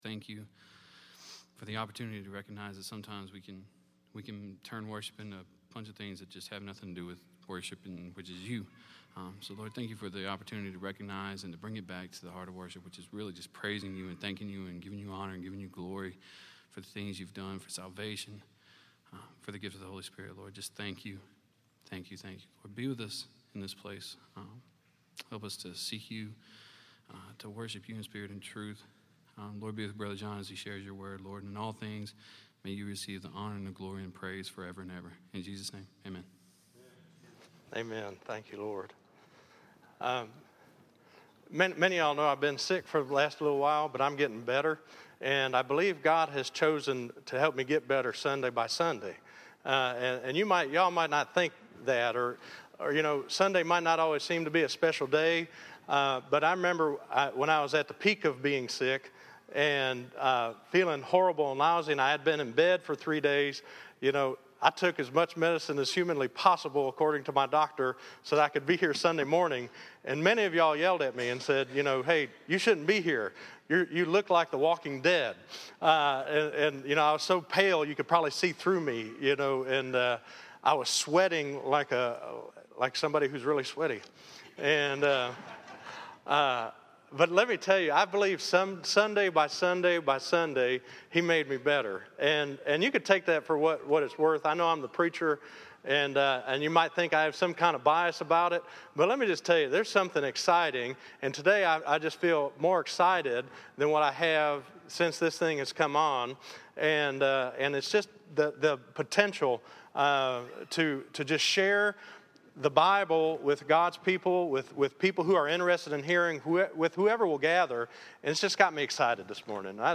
Listen to A Discriminating Love - 11_09_14_sermon.mp3